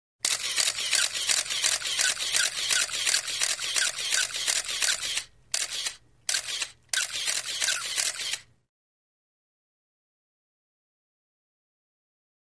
35 mm Still Camera Shooting Multiple Shots With Auto winder 1; Rapid Shutter Clicks With Motor And Servo Whine.